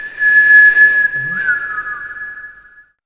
2607R WISTLE.wav